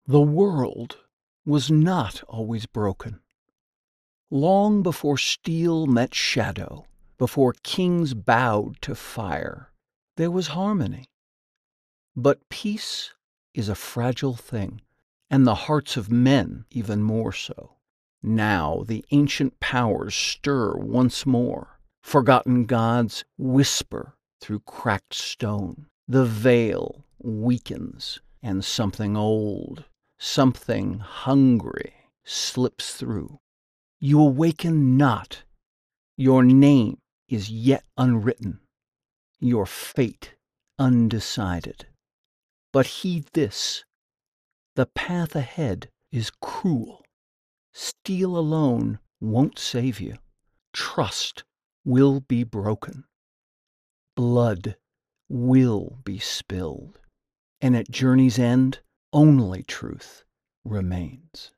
Adult (30-50) | Older Sound (50+)
0109Video_Game_VO_Older_Sound.mp3